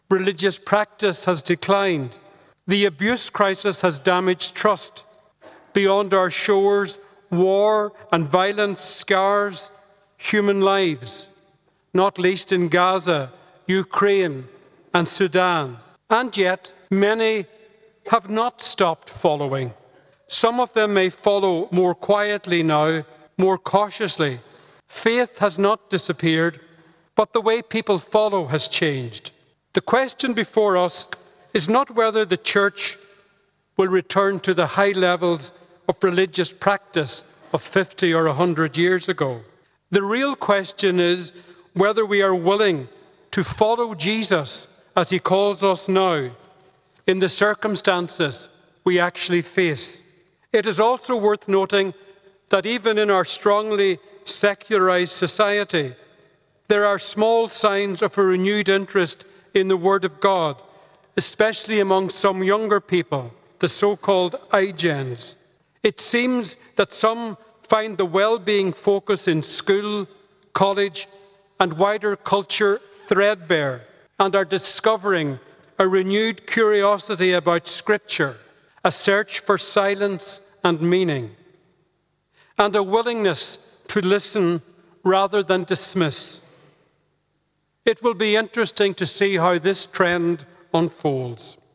He was officially installed during a ceremony in St Eunan’s Cathedral yesterday.
During his Homily for yesterday’s mass, Bishop Coll says while the world remains divided and secular, faith is growing among young people: